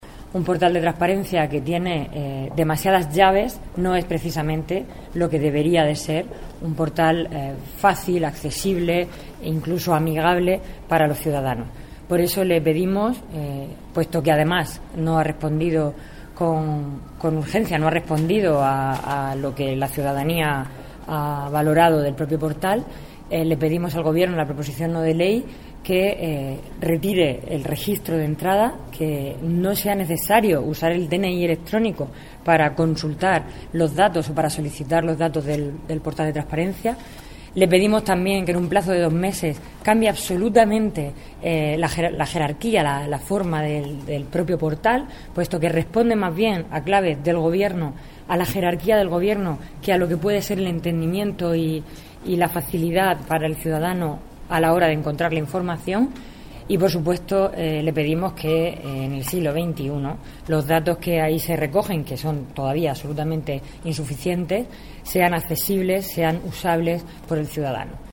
María González Veracruz hace declaraciones a los medios de comunicación el 22/12/2014 explicando el contenido de la proposición no de ley paramejorar el acceso de los ciudadanos al portal de transparencia del gobierno